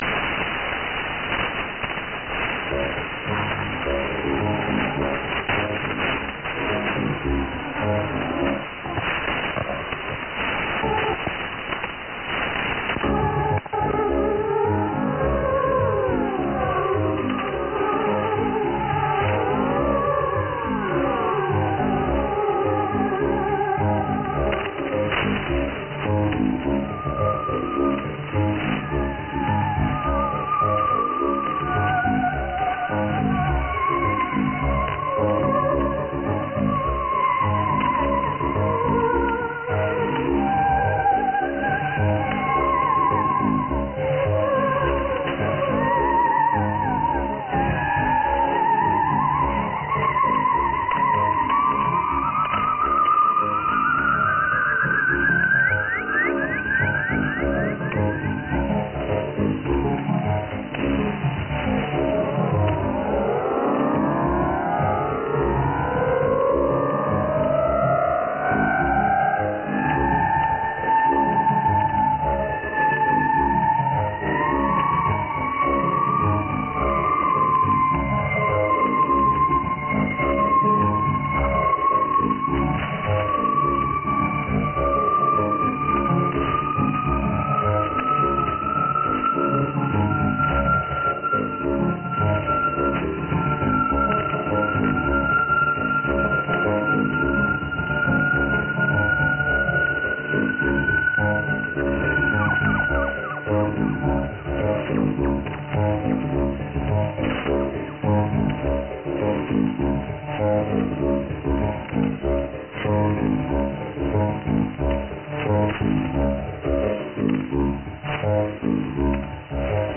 SDR recording catch 0045 Signed on with some unshazzamable spacey music. Excellent signal.